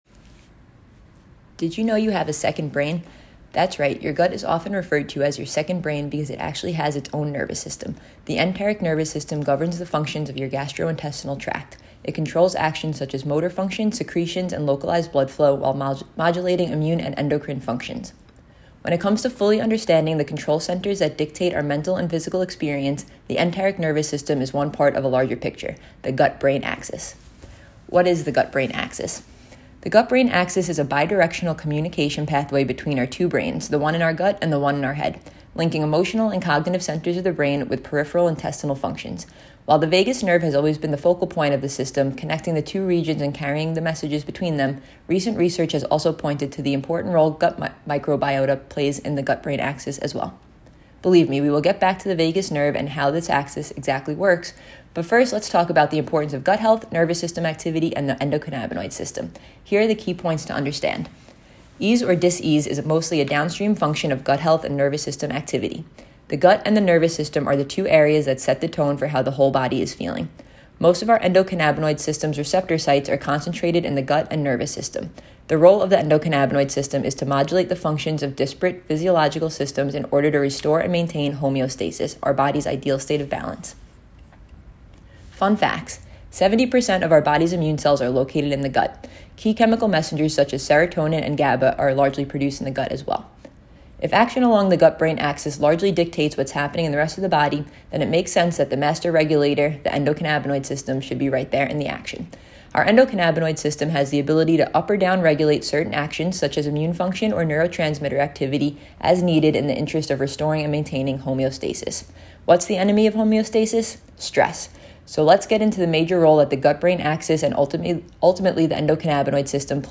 gut-brain-reading.m4a